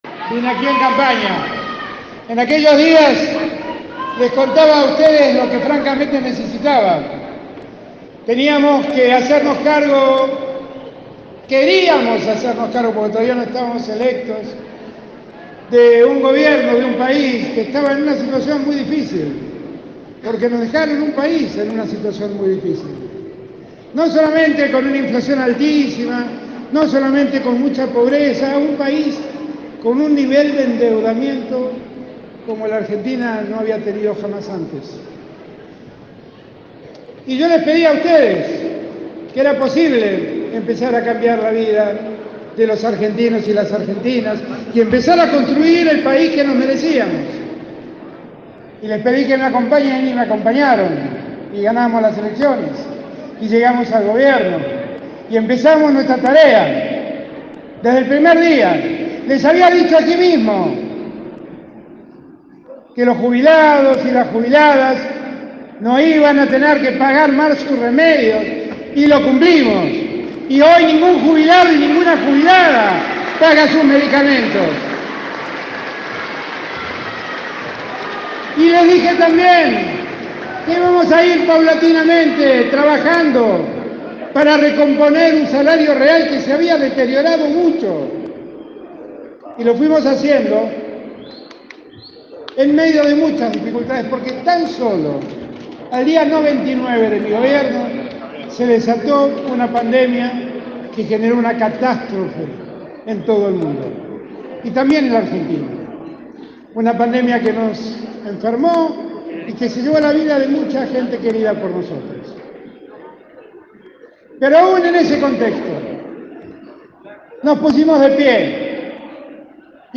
El presidente Alberto Fernández arribó esta tarde a la provincia y en acto que se llevó a cabo en el predio de los trabajadores de la sanidad, destacó la atención que puso su administración en la salud.